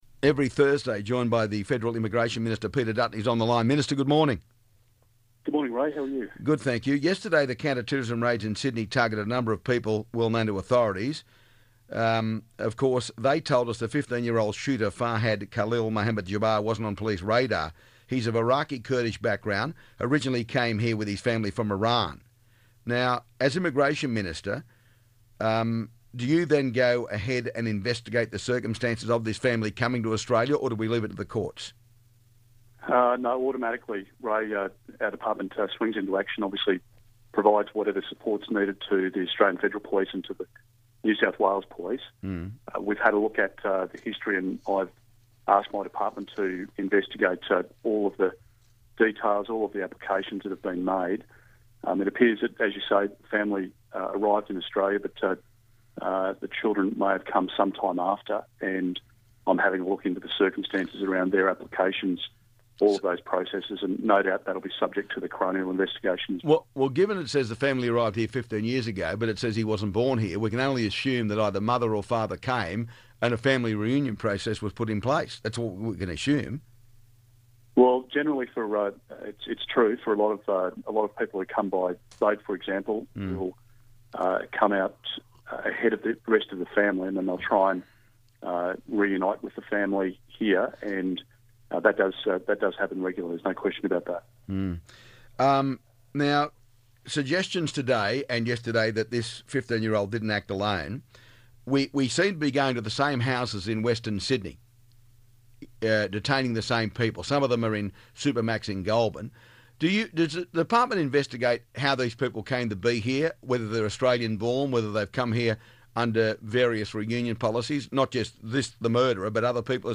Ray Hadley - Peter Dutton Interview